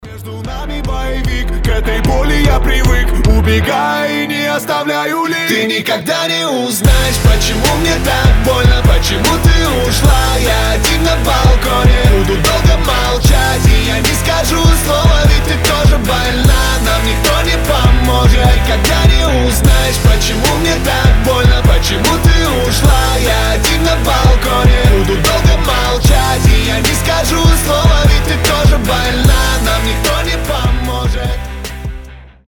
гитара
громкие
Драйвовые